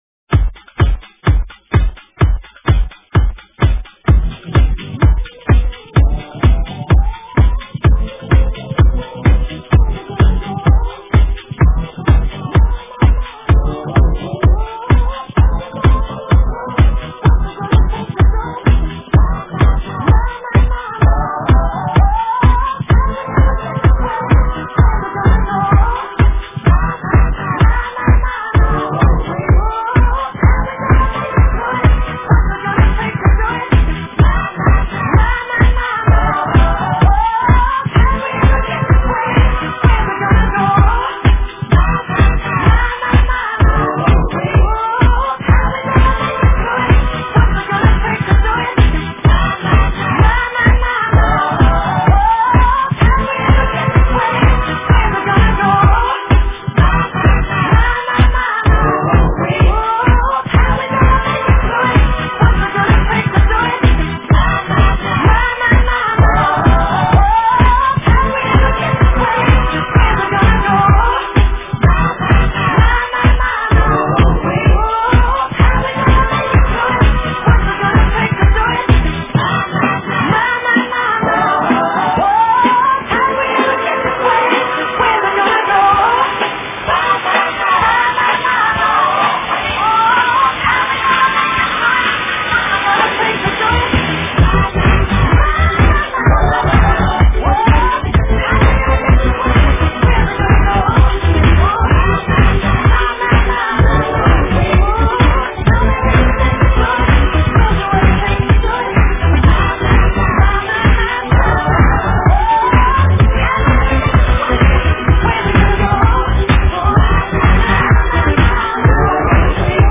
DNB